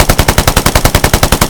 smg-mid-2.ogg